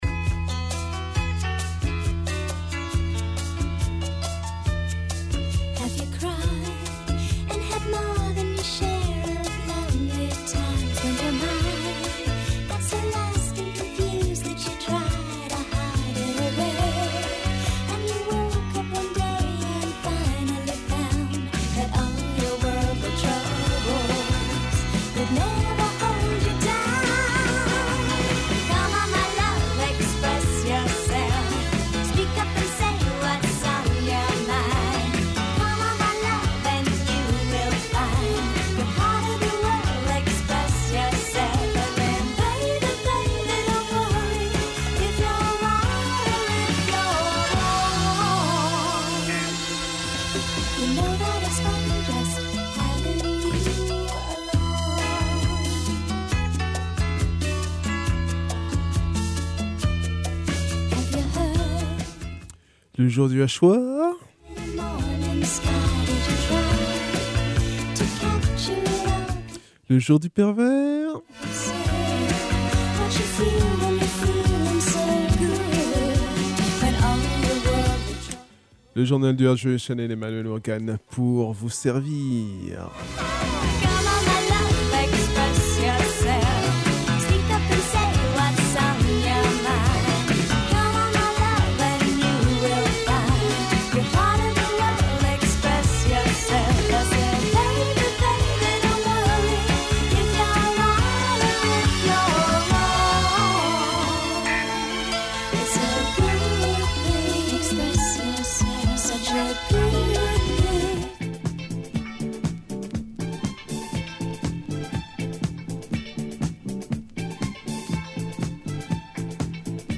en live.
plutôt ska